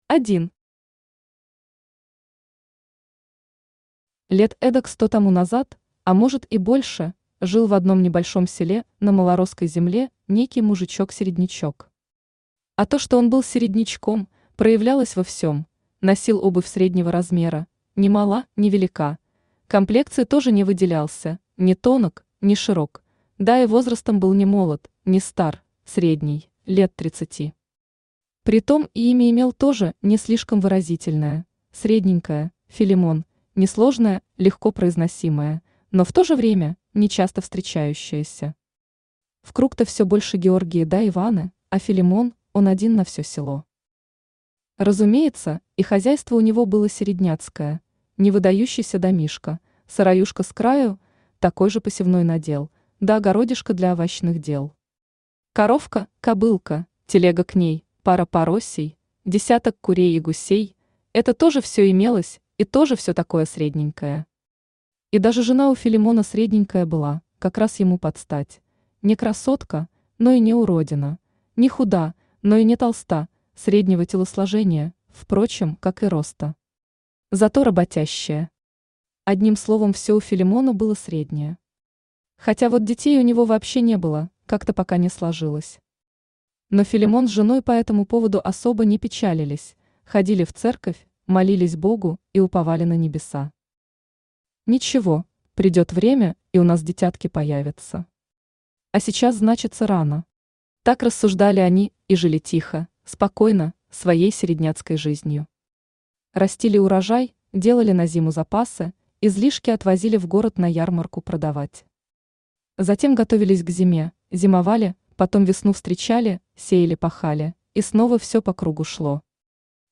Аудиокнига Сказка о середняке Филимоне | Библиотека аудиокниг
Aудиокнига Сказка о середняке Филимоне Автор Игорь Дасиевич Шиповских Читает аудиокнигу Авточтец ЛитРес.